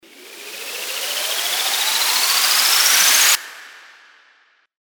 FX-752-RISER
FX-752-RISER.mp3